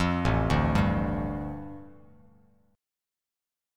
A7sus4#5 chord